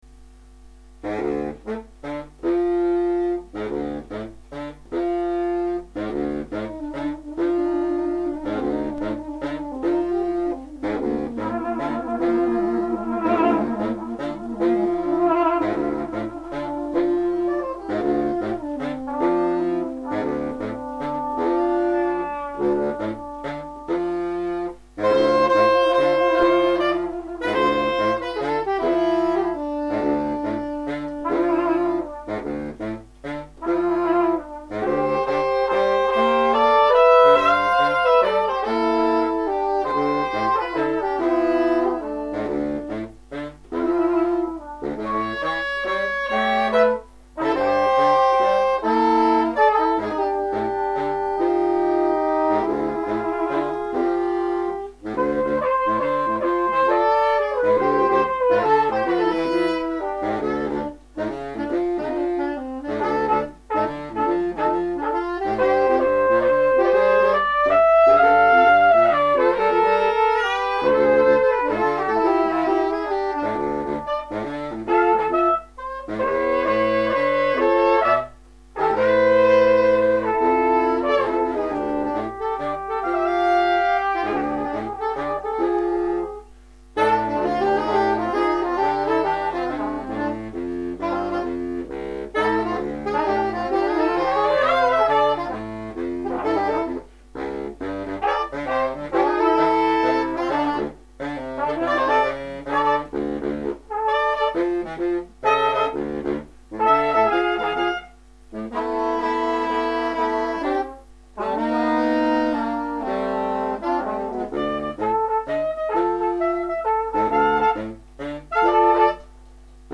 ss, bars, tp/flh, !perf
· Genre (Stil): Jazz